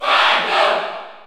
File:Falco Cheer French PAL SSBU.ogg
Category: Crowd cheers (SSBU) You cannot overwrite this file.